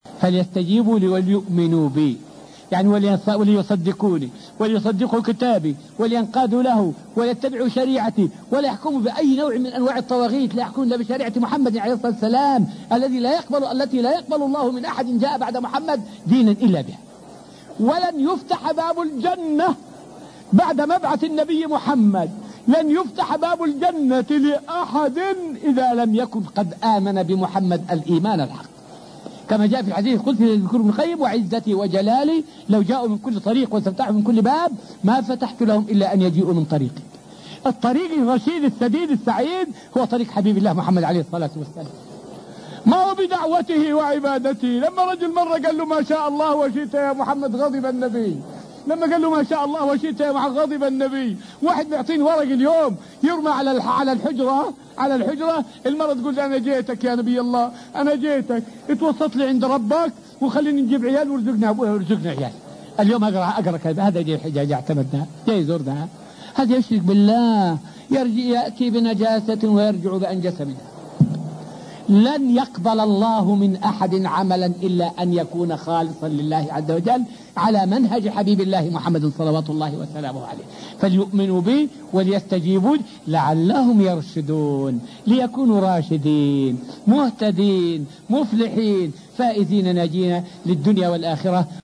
فائدة من الدرس الرابع والعشرون من دروس تفسير سورة البقرة والتي ألقيت في المسجد النبوي الشريف حول أنه لا إيمان لمن لم يؤمن بمحمد عليه السلام.